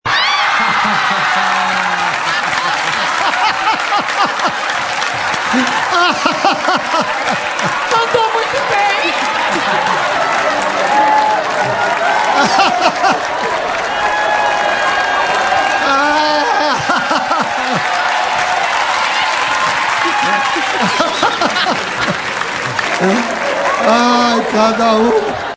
Risada Serginho Groisman
Serginho Groisman, Laura Muller e plateia caem na gargalhada no Altas Horas.
risada-serginho-groisman-altas-horas.mp3